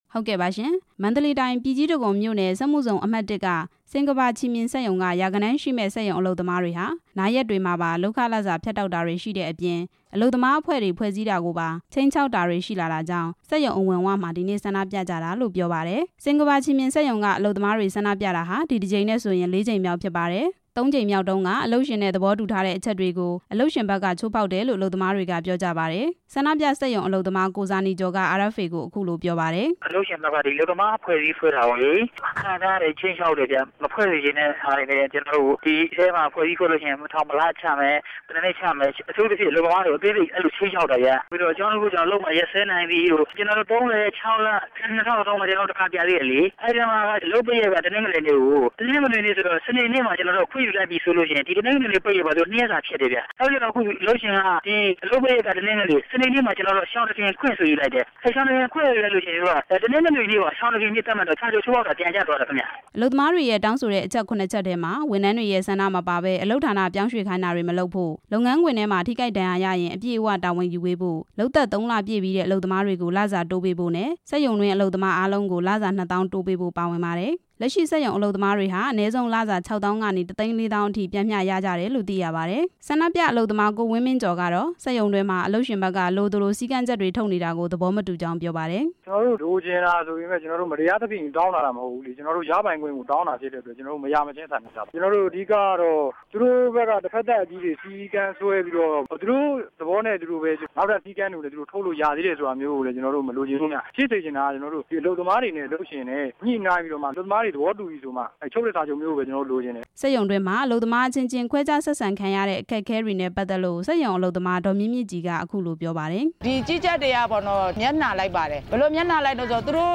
ဆန္ဒပြတာနဲ့ ပတ်သက်ပြီး တင်ပြချက်